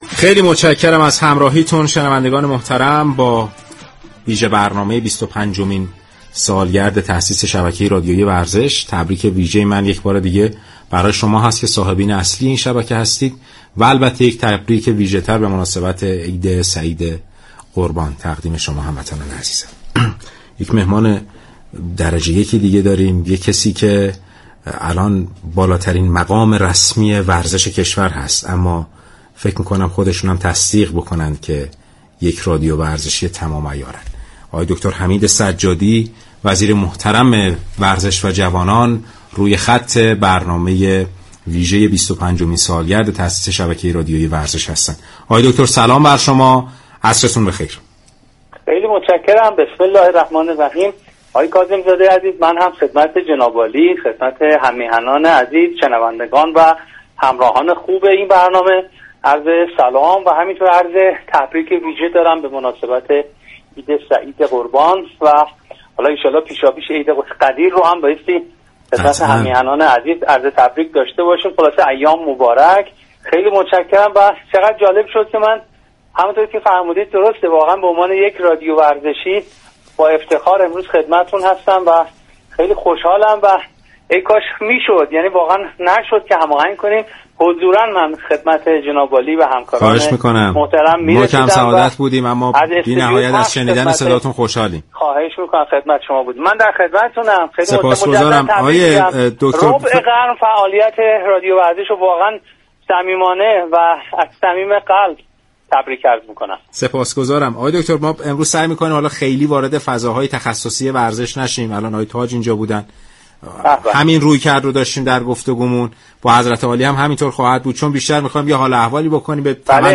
سید حمید سجادی، وزیر ورزش و جوانان و از پیشكسوتان رادیو ورزش در گفت‌و‌گو با رادیو ورزش سالروز تولد این شبكه و عید سعید قربان را صمیمانه تبریك گفت.